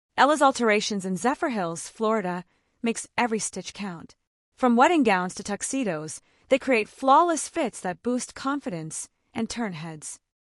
Audio narration of the article ‘Look Flawless at Every Event’ from Ella’s Alterations in Zephyrhills, Florida.